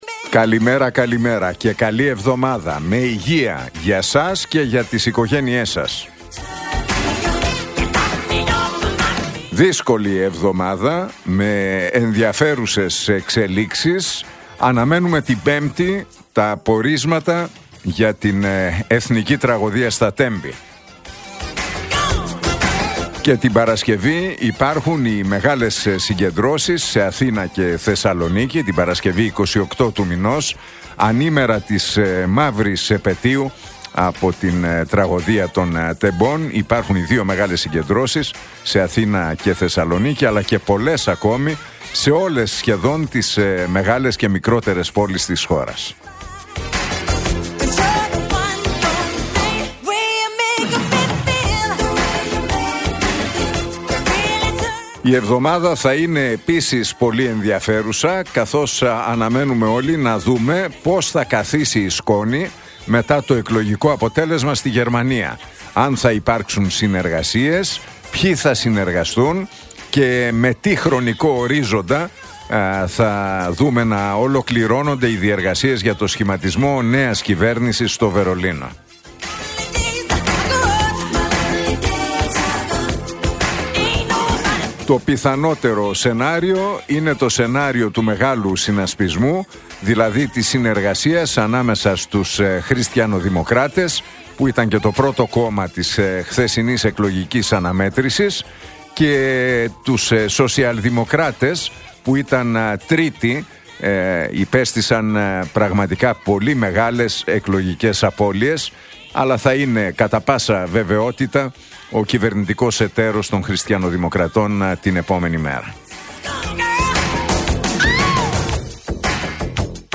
Ακούστε το σχόλιο του Νίκου Χατζηνικολάου στον ραδιοφωνικό σταθμό RealFm 97,8, την Δευτέρα 24 Φεβρουαρίου 2025.